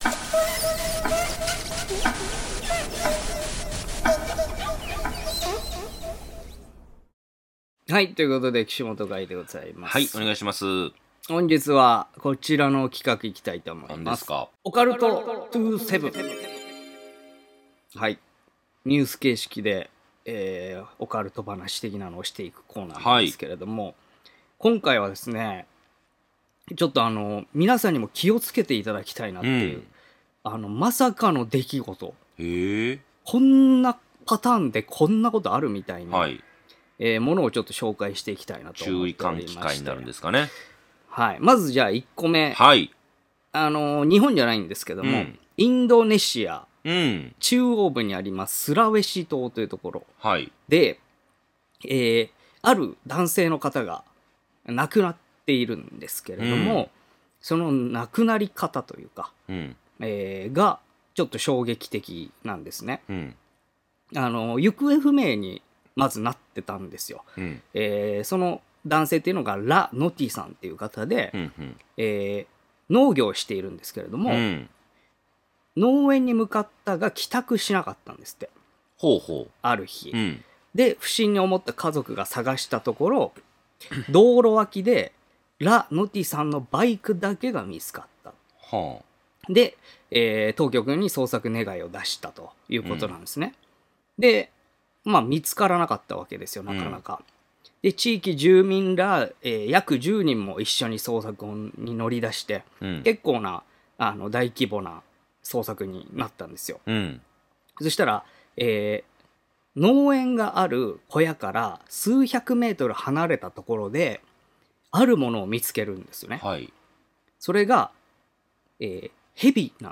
若手放送作家の２人が都市伝説や日常に忍び寄るオカルト又は眉唾な噂話を独自の目線で切りお送りしていく番組です。